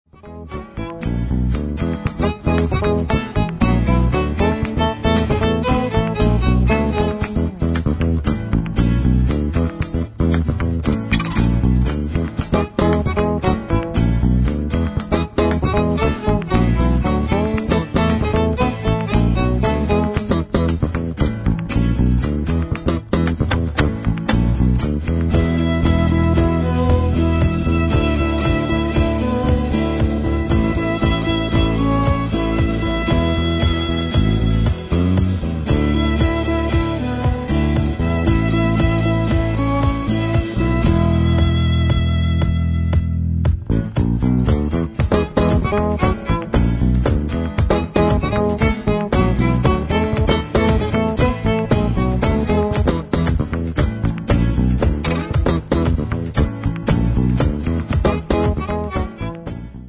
Basso e Contrabbasso
Violino